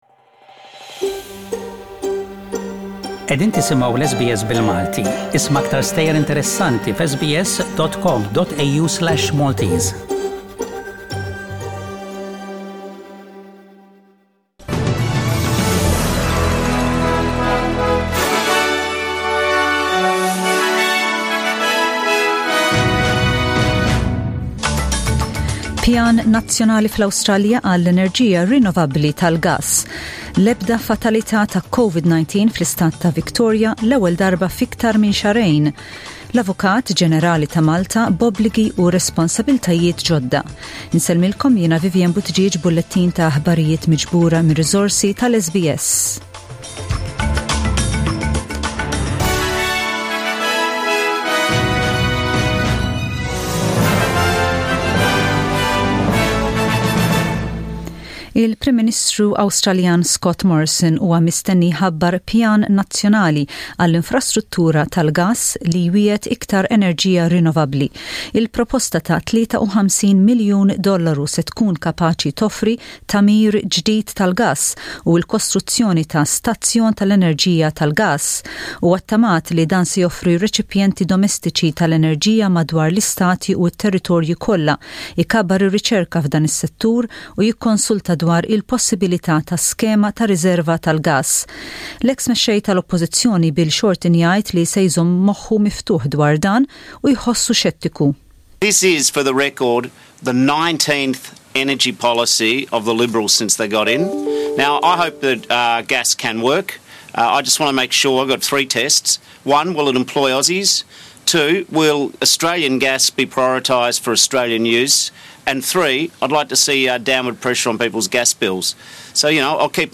SBS Radio | Aħbarijiet bil-Malti: 15/09/20